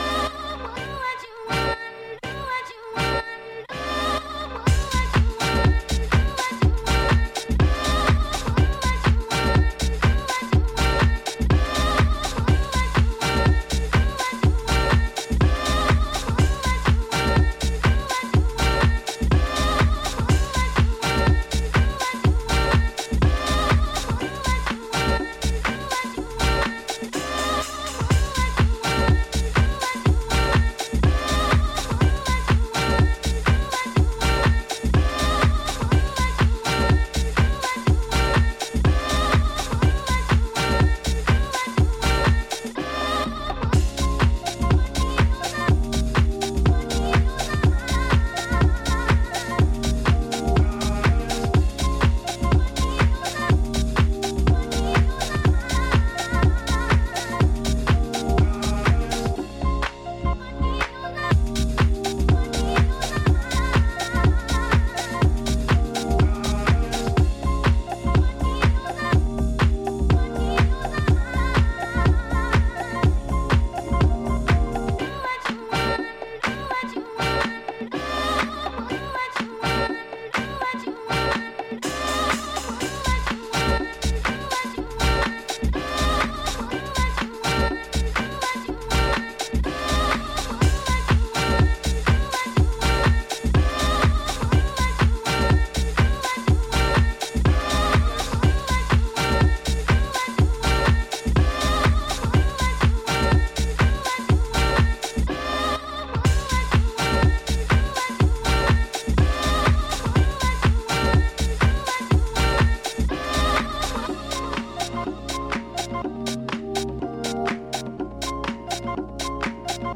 House and Disco
deeply rooted, timeless sound